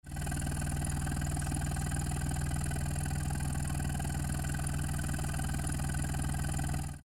GTbox06&S汎用サイレンサー（アイドリング）
汎用サイレンサーでは虫の鳴き声のような
高音の不快なビビり音が入っていますが
copen_la400-kakimoto_general_baffle_idling.mp3